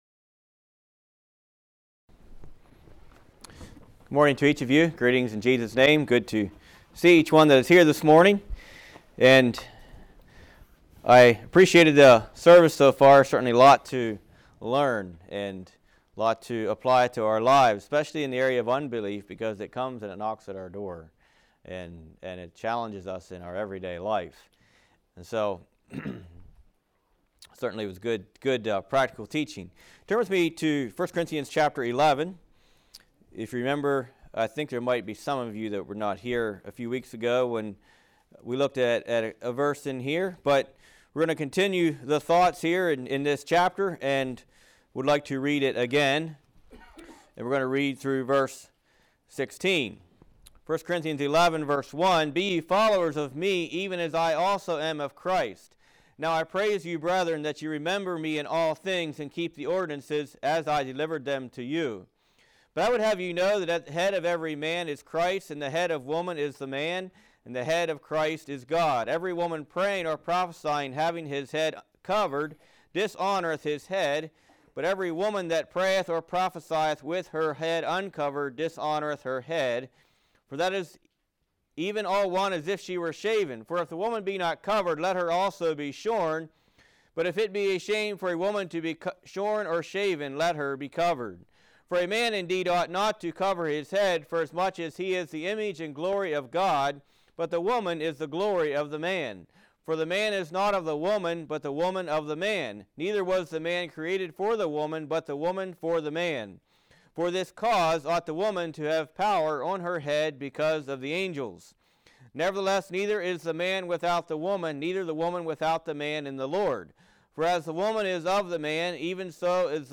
Play Now Download to Device Godly Woman's Authority Congregation: Winchester Speaker